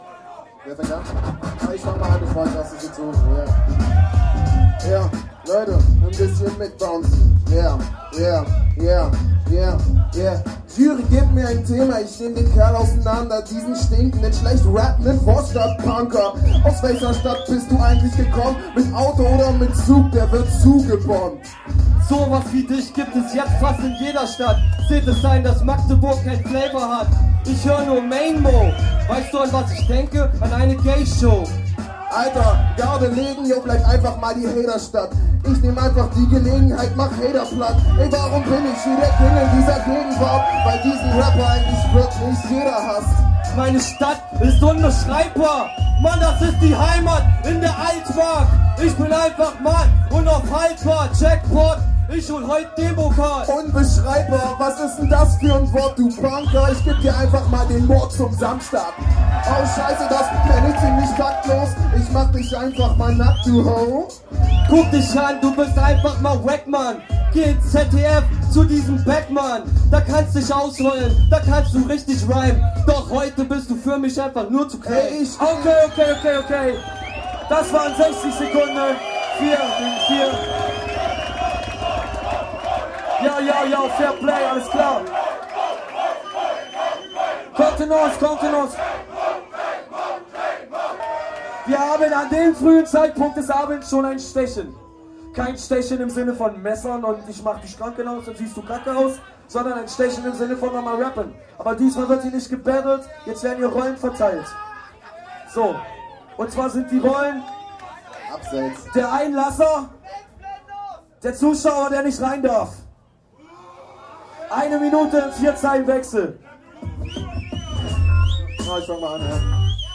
folgende MCs traten die Vorrunde an:
Das Viertelfinale: